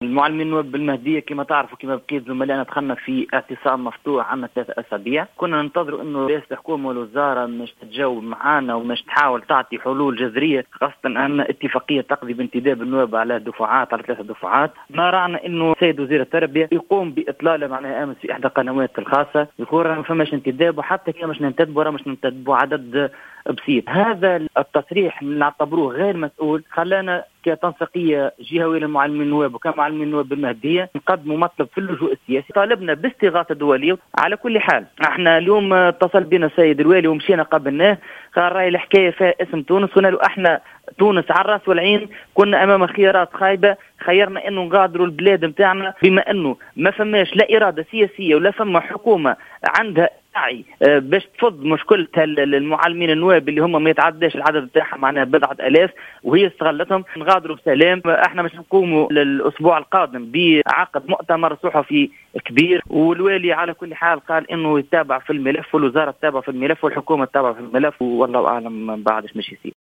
في اتصال هاتفي مع الجوهرة أف أم